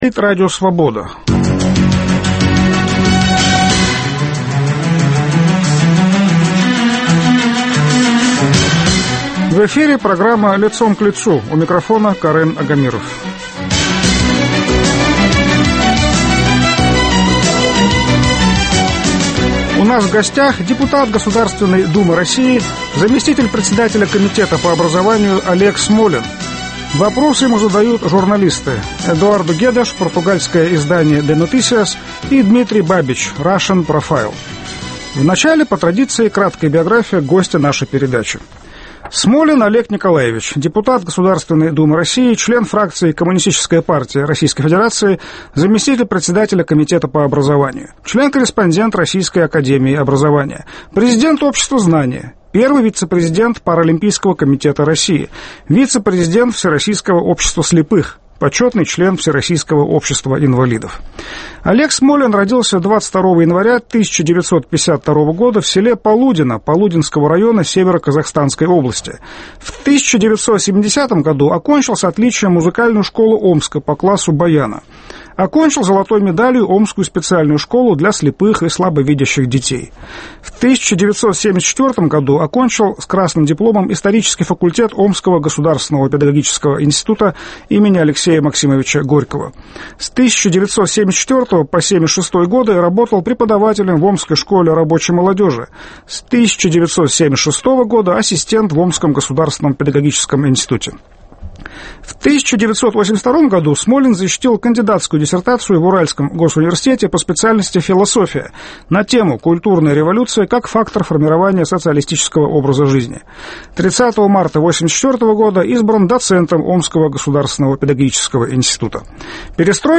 В программе - заместитель председателя Комитета Государственной Думы России по образованию Олег Смолин.